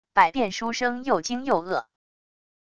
百变书生又惊又愕wav音频